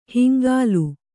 ♪ hingālu